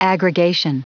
Prononciation du mot aggregation en anglais (fichier audio)
Prononciation du mot : aggregation